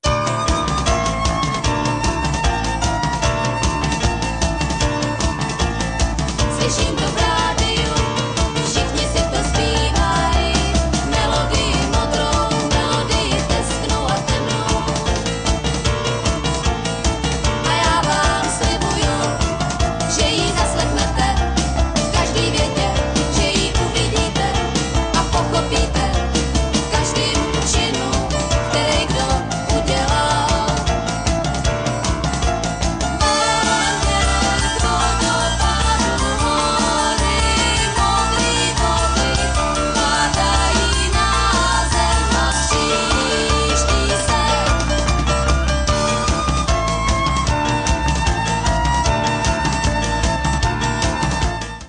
bicí
zpěv, flétna, saxofony
zpěv, klavír
zpěv, baskytara
housle